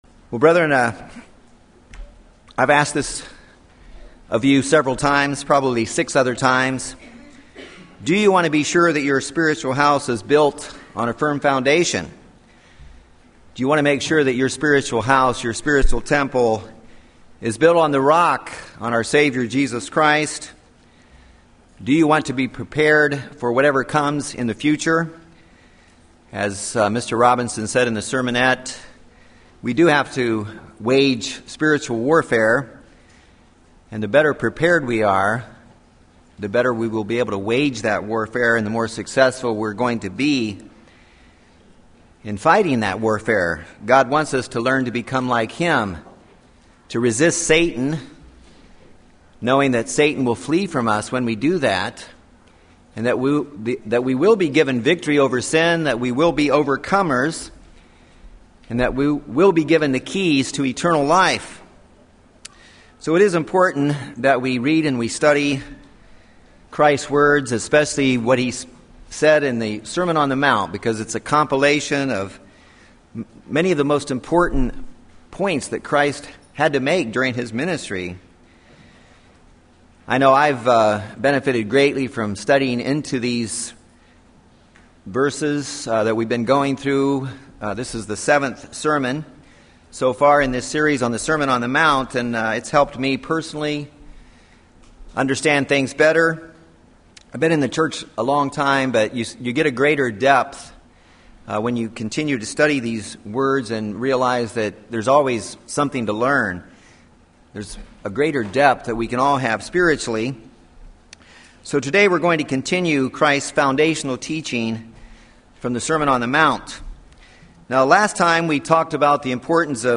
Sermon on the Mount | United Church of God